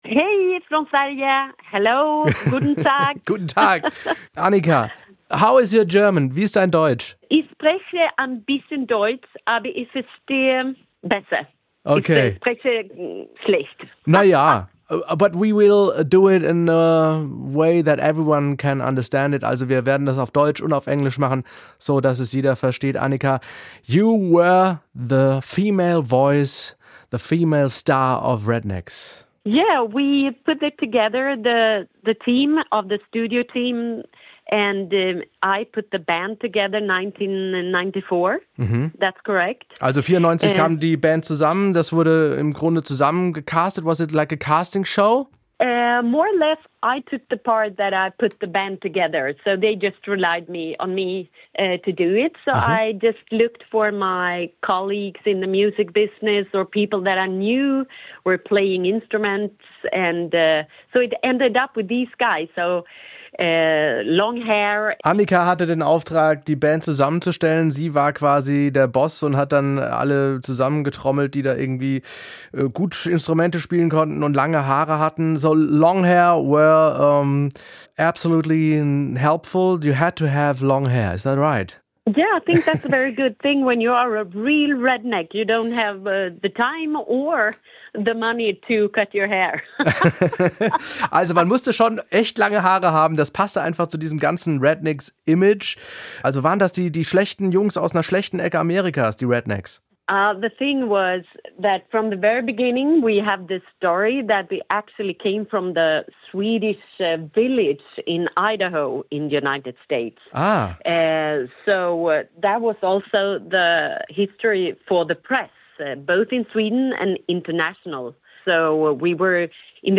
Annika Ljungberg, die Sängerin der Rednex im Interview
annika-ljungberg-von-rednex-deutschland-war-wie-ein-zweites-zuhause.mp3